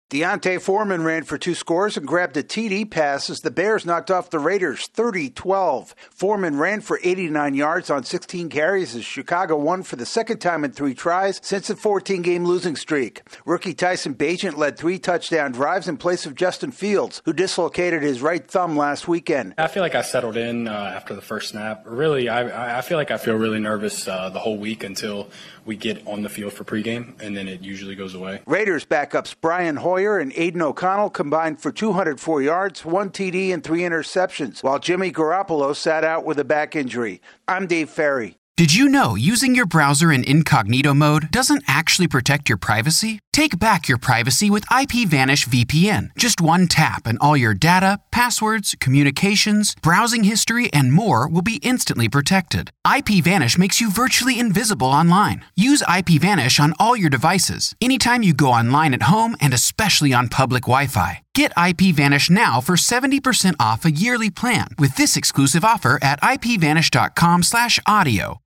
The Bears win with a backup quarterback. AP correspondent